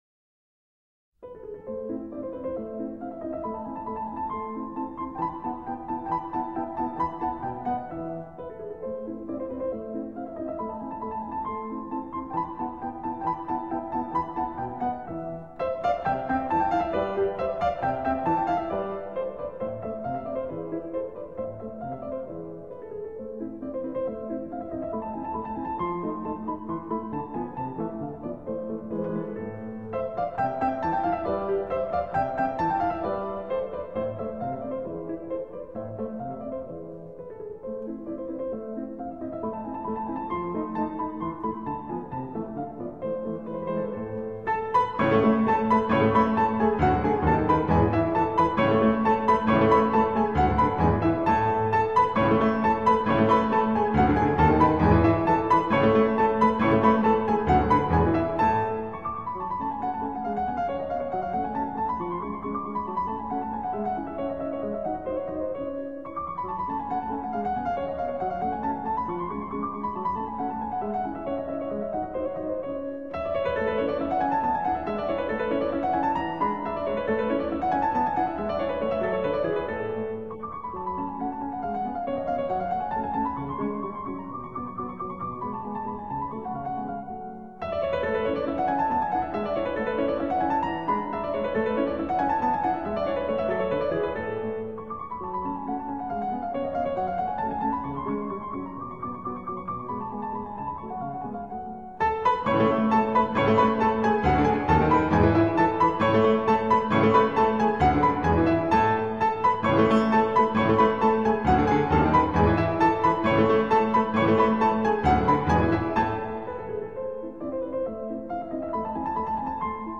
guitar, congas, drums (cymbal), bass, Saxophone